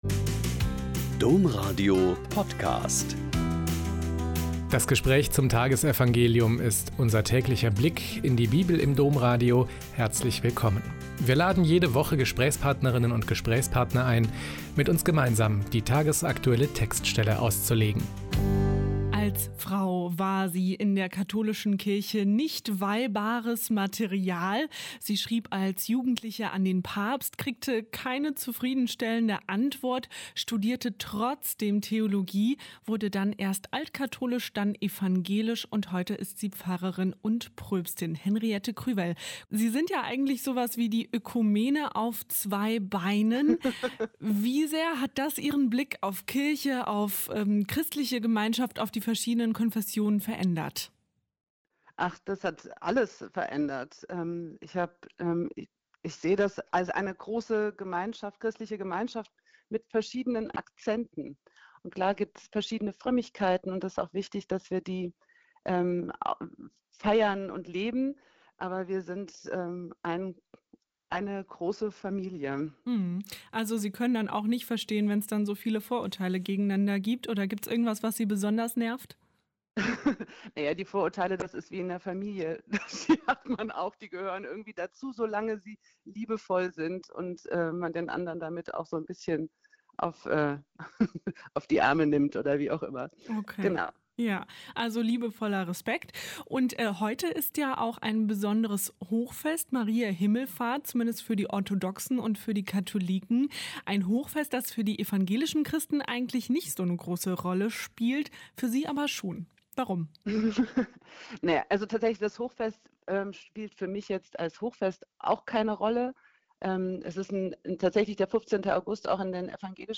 Lk 1,39-56 - Gespräch